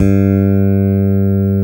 Index of /90_sSampleCDs/Roland LCDP02 Guitar and Bass/BS _E.Bass v_s/BS _5str v_s